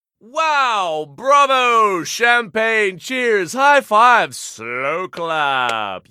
wow-bravo-champagne-slow-clap